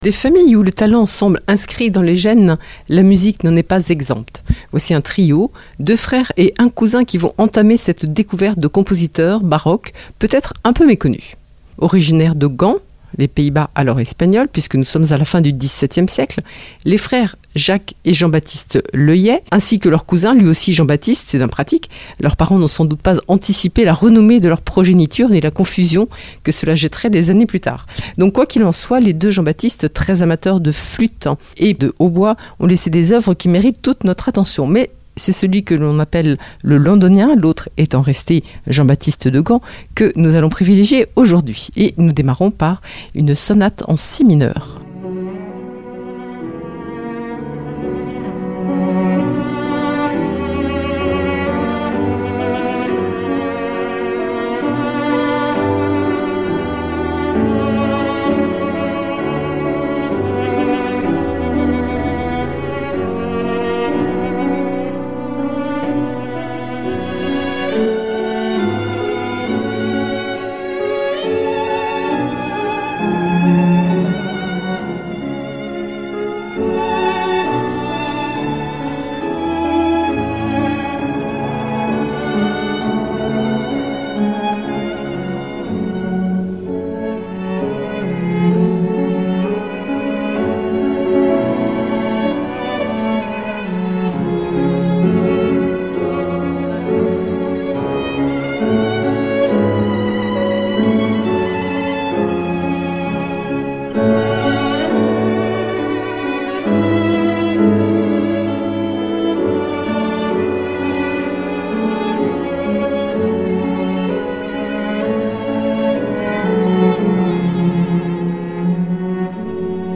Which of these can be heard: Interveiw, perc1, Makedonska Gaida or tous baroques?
tous baroques